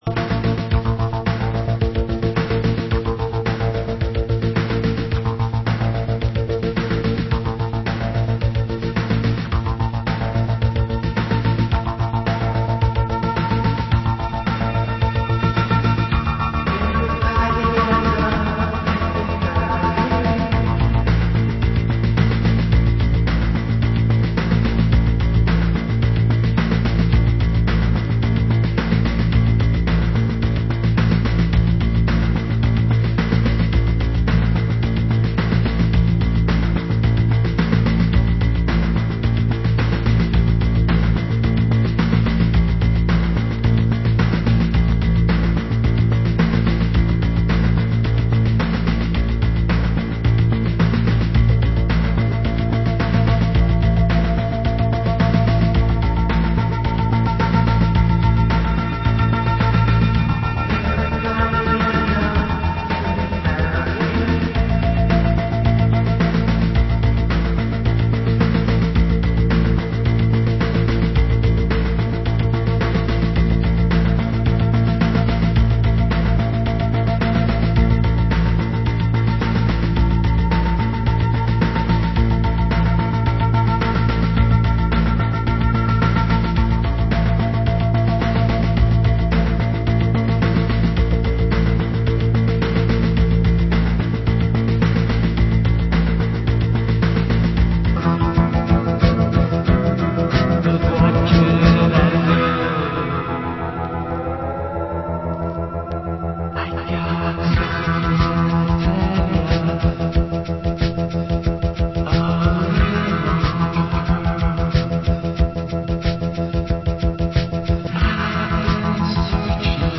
Genre: Indie Dance